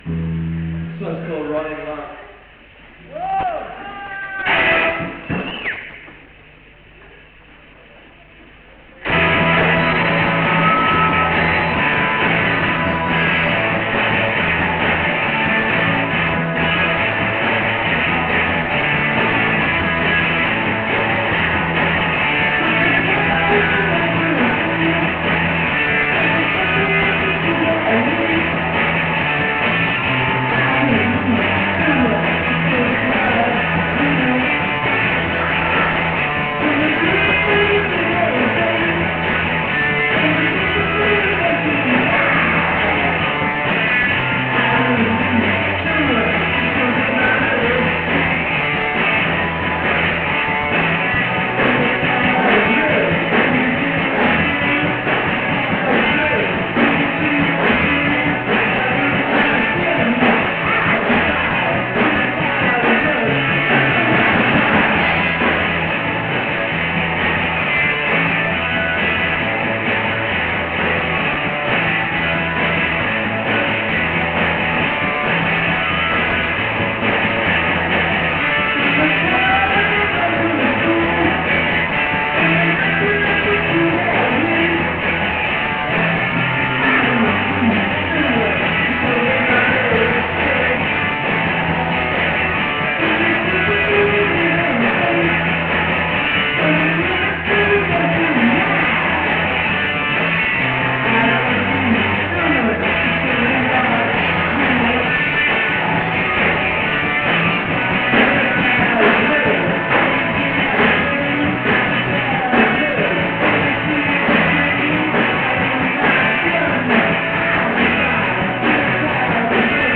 Drums
bass
vocals